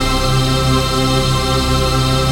DM PAD2-26.wav